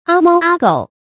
阿猫阿狗 ā māo ā gǒu
阿猫阿狗发音
成语注音 ㄚ ㄇㄠ ㄚ ㄍㄡˇ